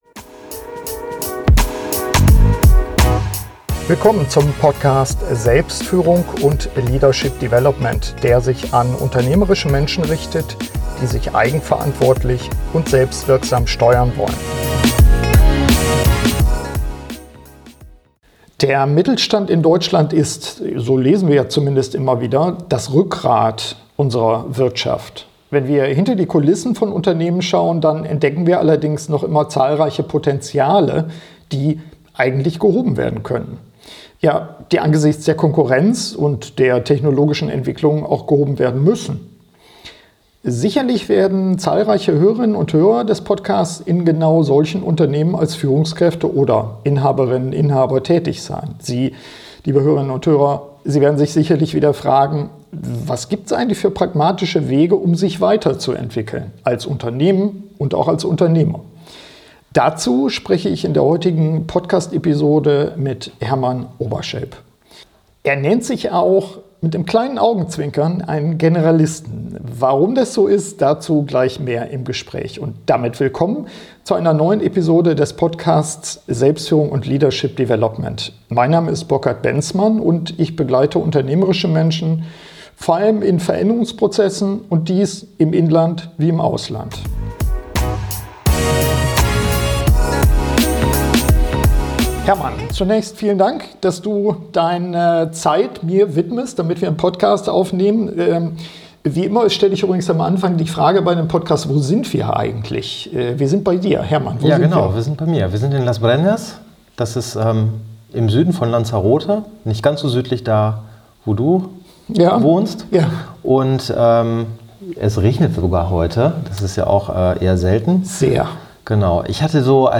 SF181 Wie kommen Mittelständler auf Kurs? Gespräch